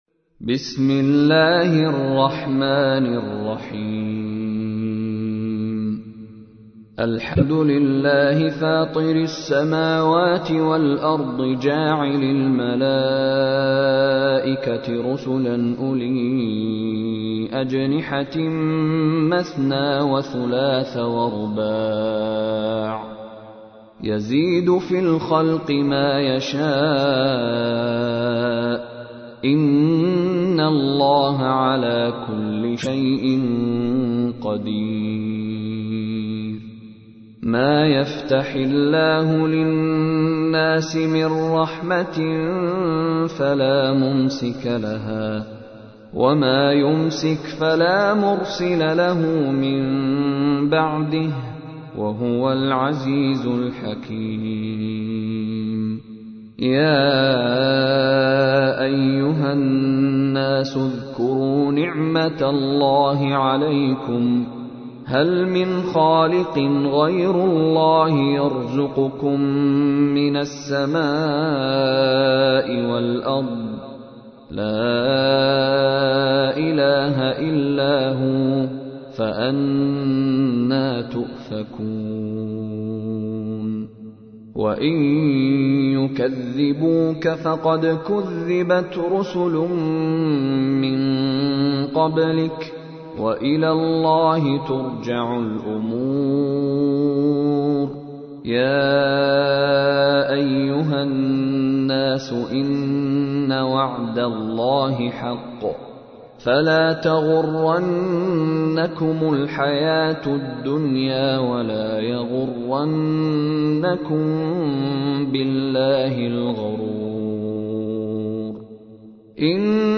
تحميل : 35. سورة فاطر / القارئ مشاري راشد العفاسي / القرآن الكريم / موقع يا حسين